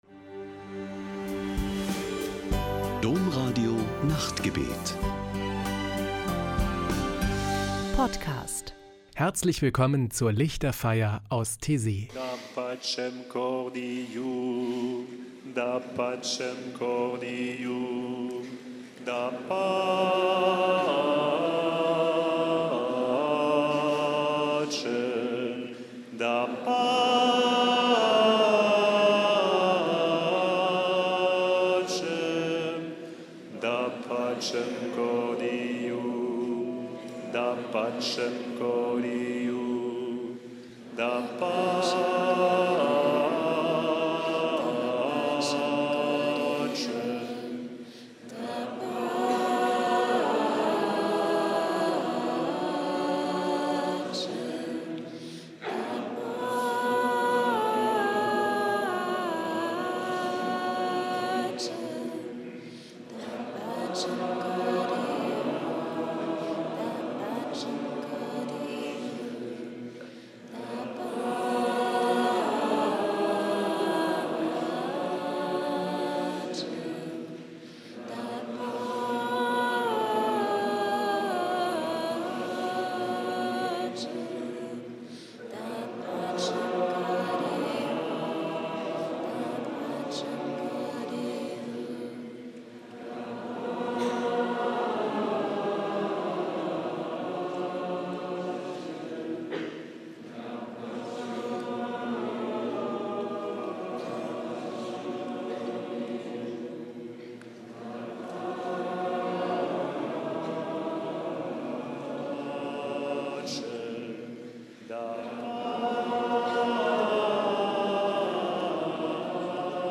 Die Lichterfeier aus Taizé: Spirituelle Gesänge und Gebete
Ein Höhepunkt jede Woche ist am Samstagabend die Lichterfeier mit meditativen Gesängen und Gebeten.